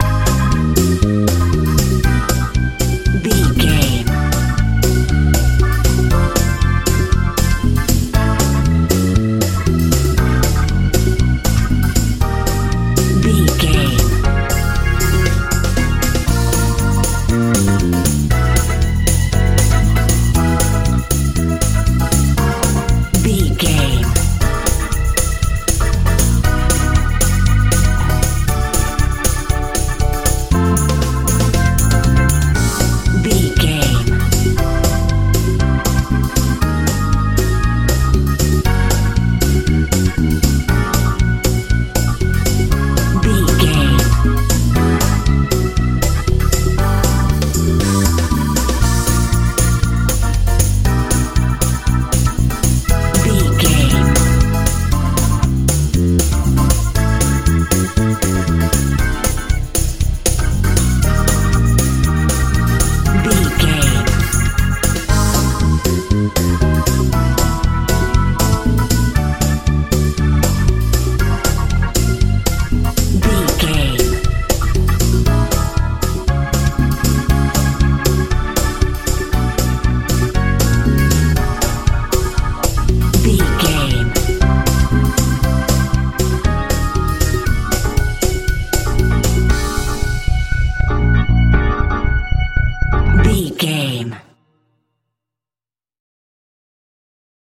praise feel
Ionian/Major
A♭
groovy
fun
conga
organ
bass guitar
drums